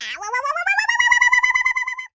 yoshi_waaaooow.ogg